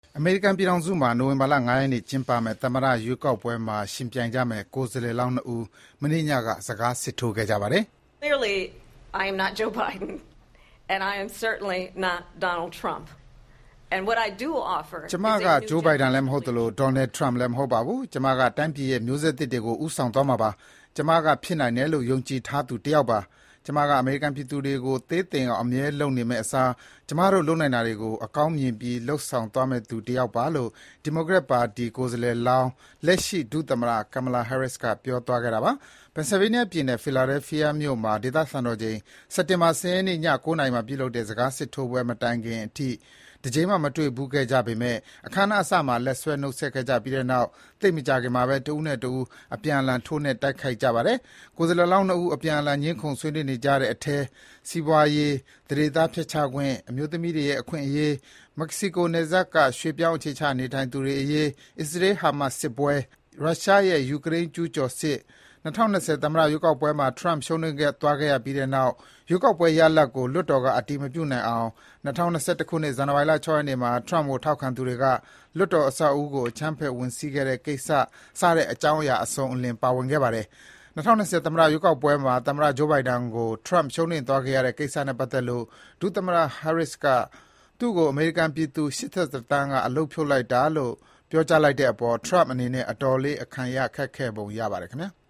Trump နဲ့ Harris တို့နှစ်ဦး စကားစစ်ထိုးနေကြစဉ် (စက်တင်ဘာ ၁၀၊ ၂၀၂၄)